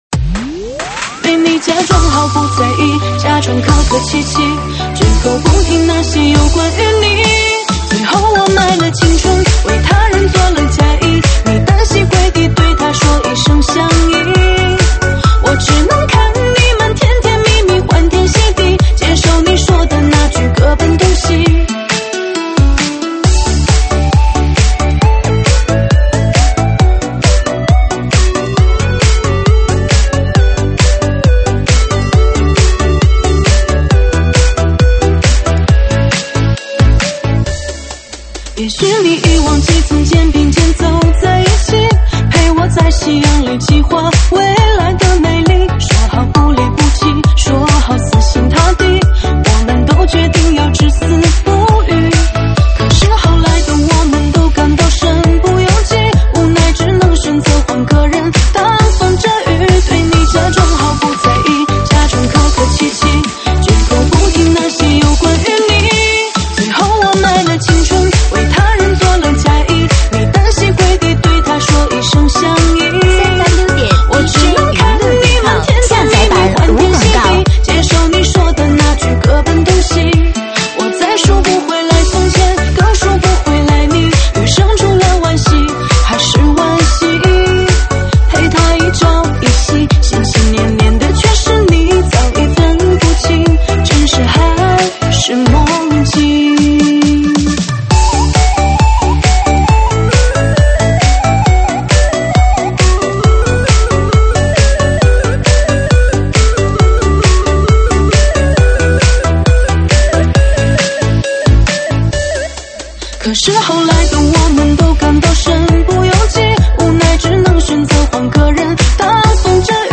现场串烧